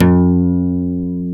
Index of /90_sSampleCDs/Roland L-CDX-01/GTR_Nylon String/GTR_Nylon Chorus